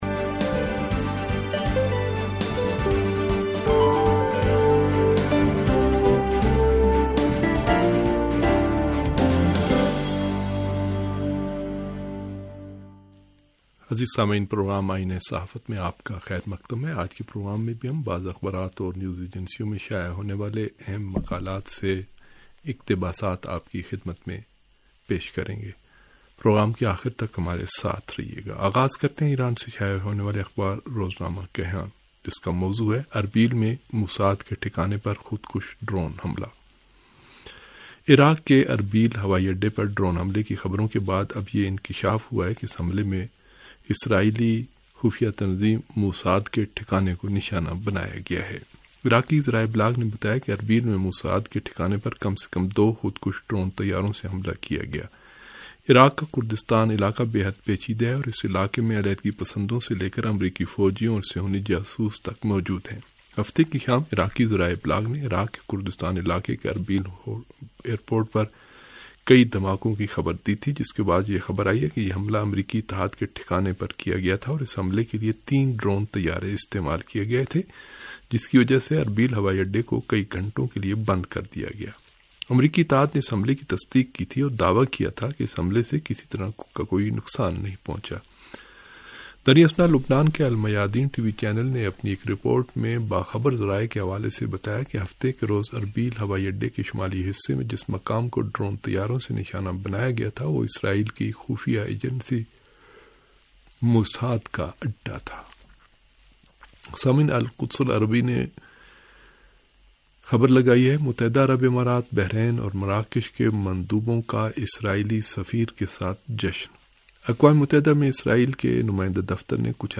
ریڈیو تہران کا اخبارات کے جائزے پرمبنی پروگرام آئینہ صحافت